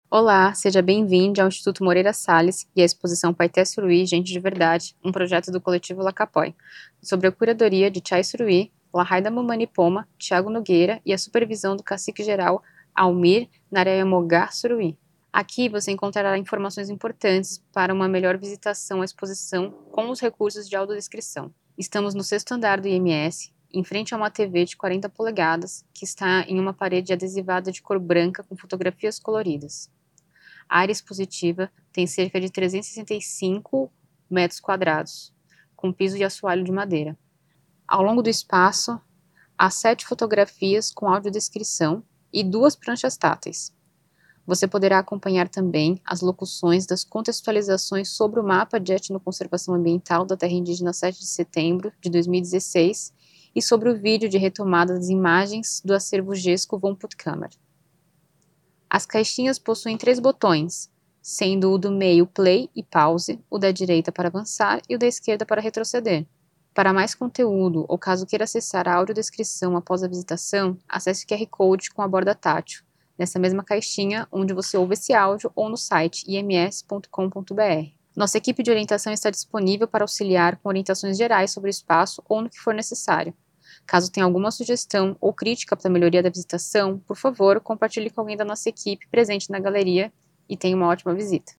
Paiter Suruí, Gente de Verdade. Um projeto do Coletivo Lakapoy - Audiodescrição | Estação 01 - Instituto Moreira Salles